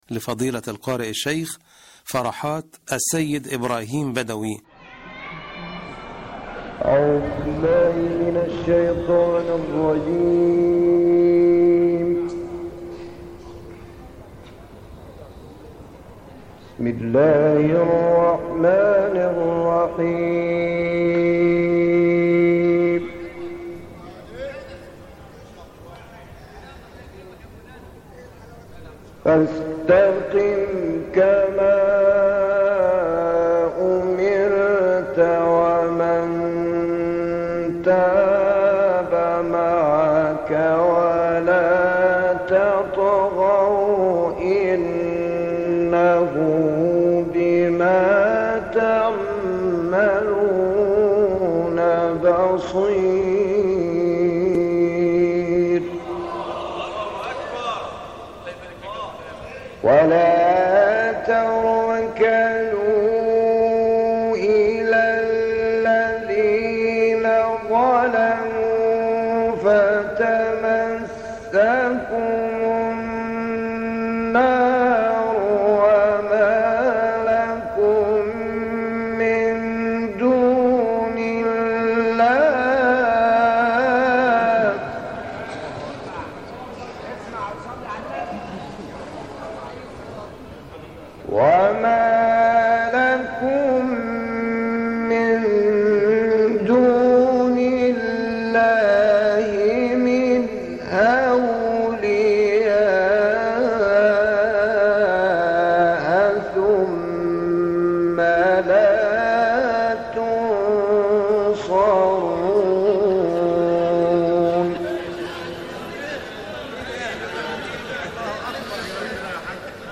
تلاوتی از قاری کمتر‌ شناخته شده مصری